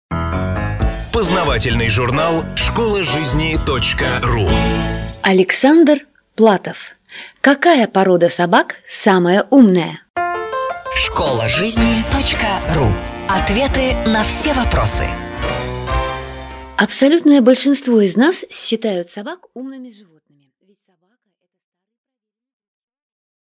Аудиокнига Какая порода собак самая умная?